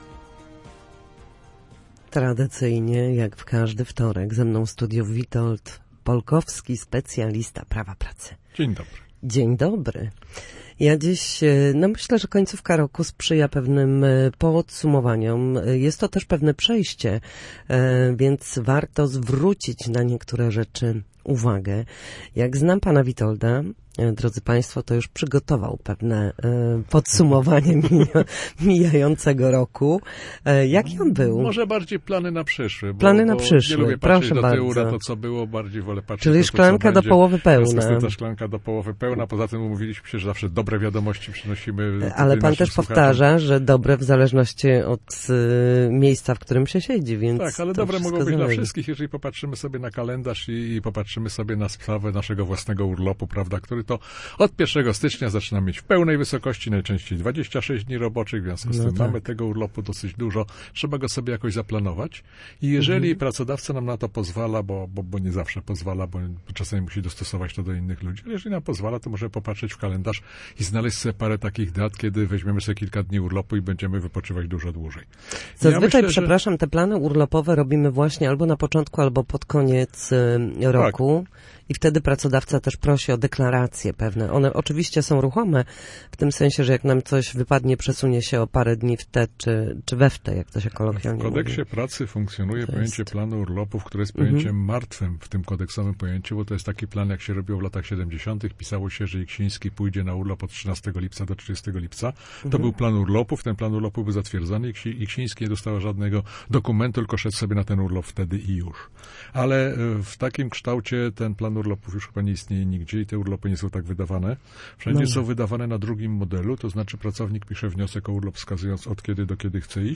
W każdy wtorek po godzinie 13 na antenie Studia Słupsk przybliżamy państwu zagadnienia dotyczące prawa pracy.